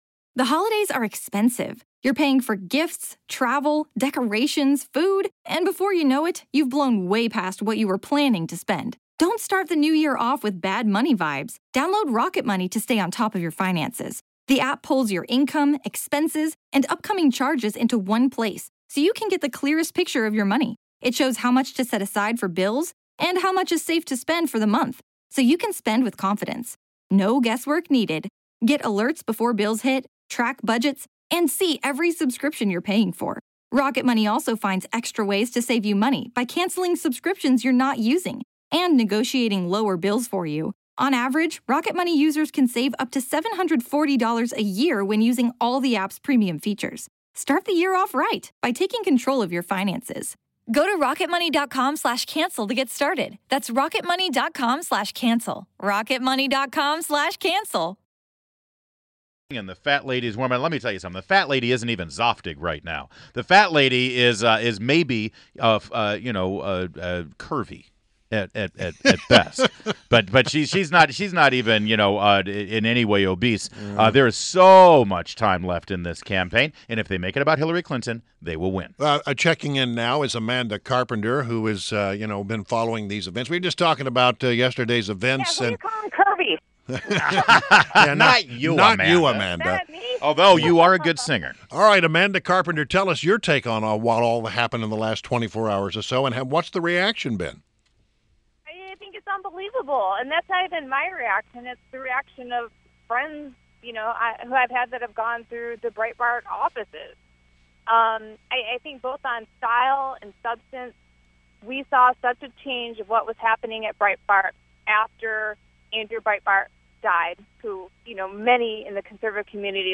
WMAL Interview - AMANDA CARPENTER - 08.18.16